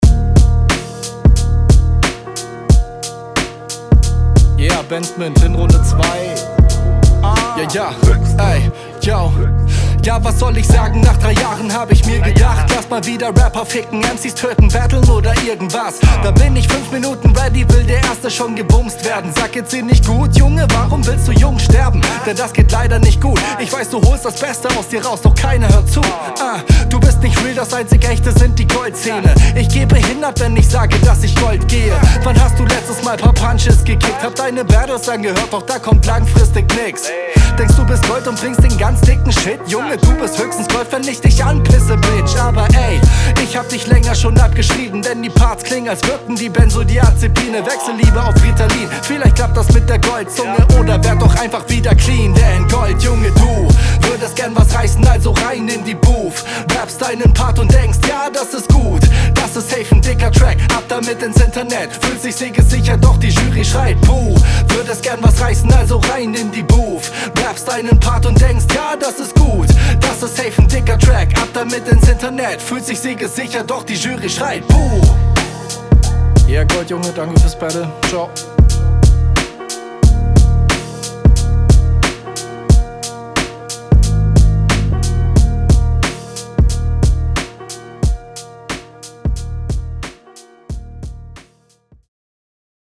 Flowtechnisch wieder stark.
Keine wirklichen Pointen, an einer Stelle auch nicjt ganz sicher auf dem Beat, aber größtenteils …